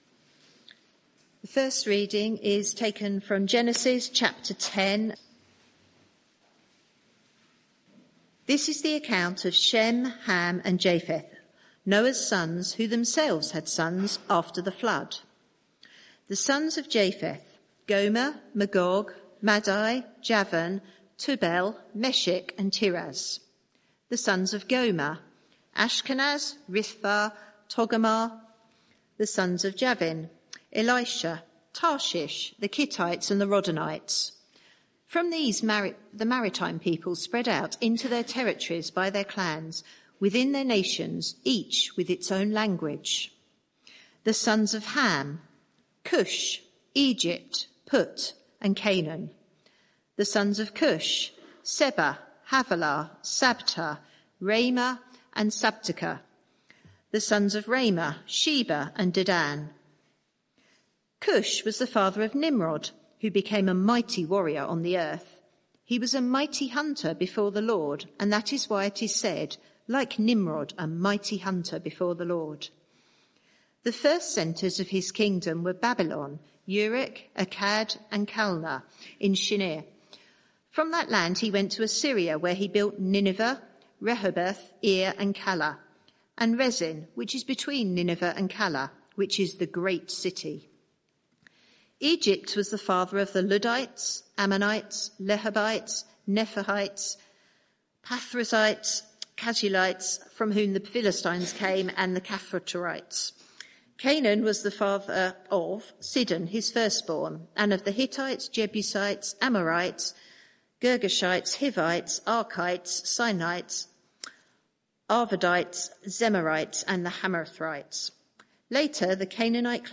Media for 6:30pm Service on Sun 01st Dec 2024 18:30 Speaker
Sermon (audio) Search the media library There are recordings here going back several years.